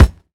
Closed Hats
Hat (50).wav